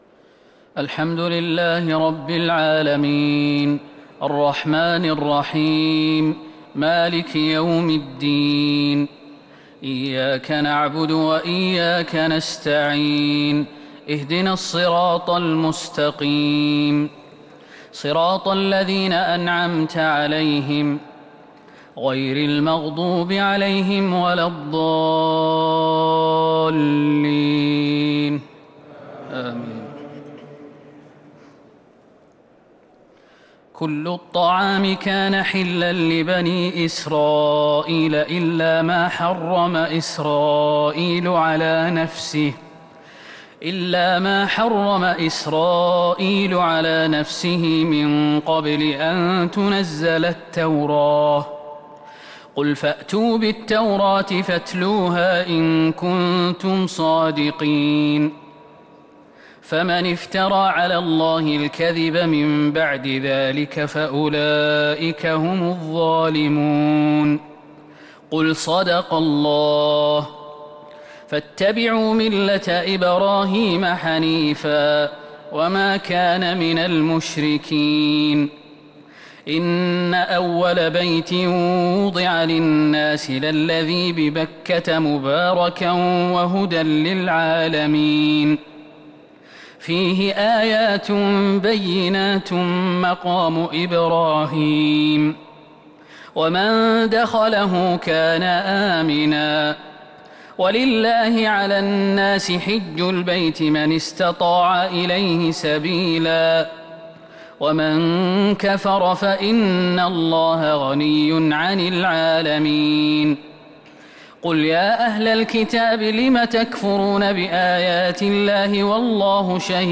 صلاة التراويح | ليلة ٥ رمضان ١٤٤٢هـ | سورة آل عمران 93-155 | Taraweeh 5th night Ramadan 1442H > تراويح الحرم النبوي عام 1442 🕌 > التراويح - تلاوات الحرمين